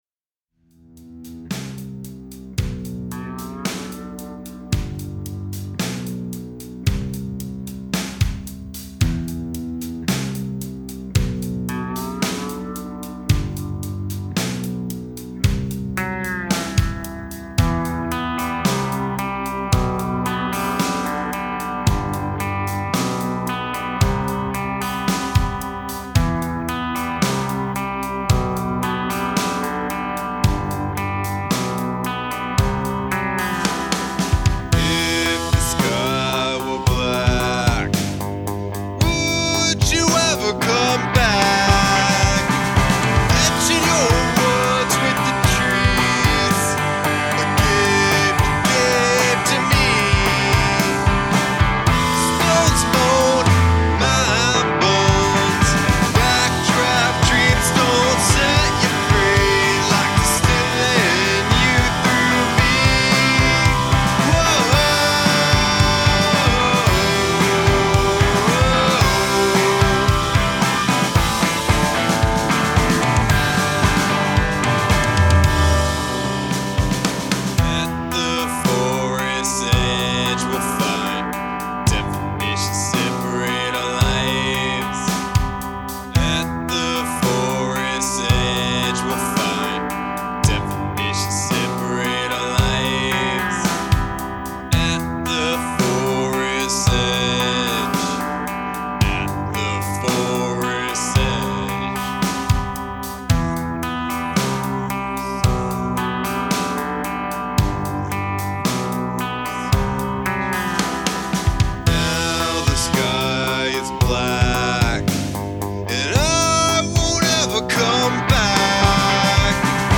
soothing sound